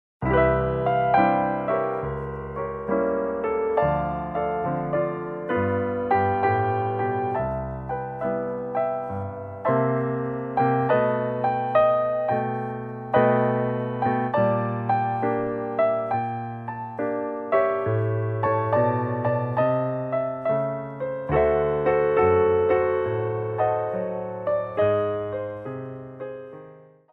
Piano Arrangements
SLOW TEMPO